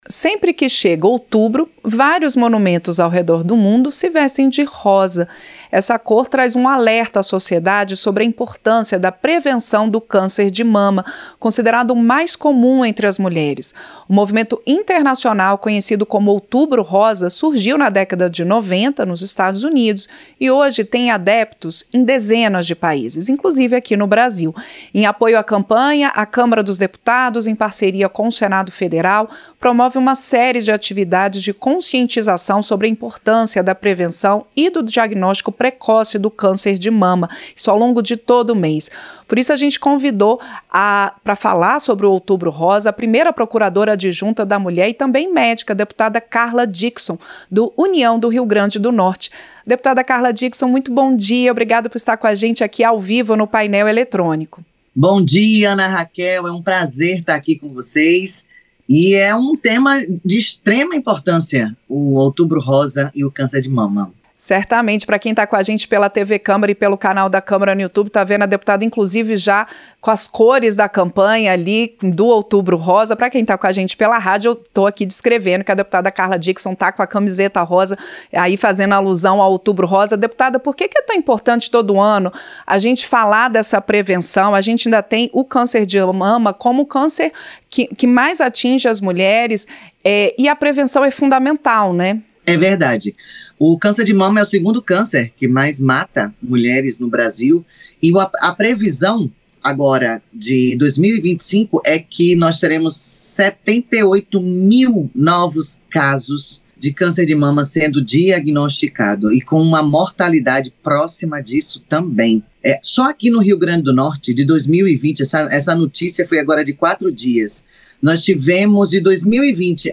Entrevista - Dep. Carla Dickson (União-RN)